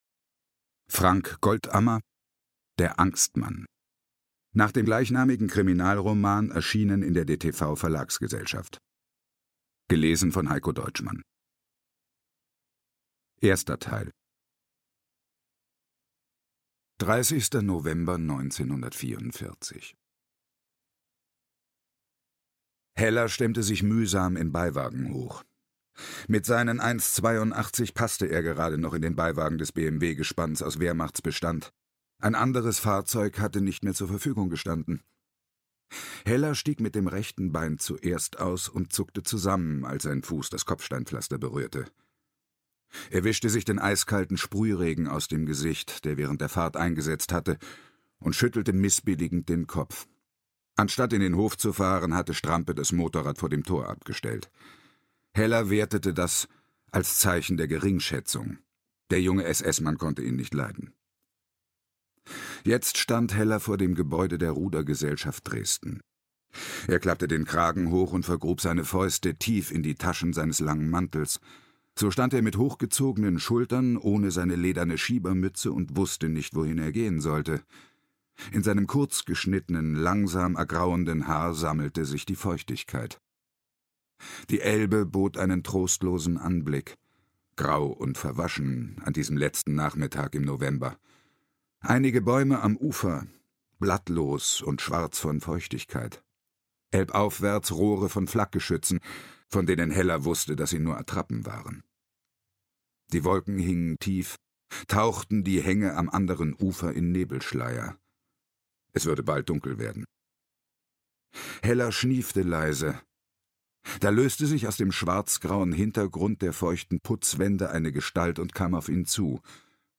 Ungekürzte Lesungen der Fälle 1 bis 5 mit Heikko Deutschmann (5 mp3-CDs)
Heikko Deutschmann (Sprecher)
Mit der sonoren und zugleich variantenreichen Stimme von Heikko Deutschmann tauchen die Hörer tief ab in vergangene, gefährliche Zeiten - atemberaubend spannend und historisch exakt recherchiert!Ungekürzte Lesung mit Heikko Deutschmann5 mp3-CDs | ca. 54 h 31 min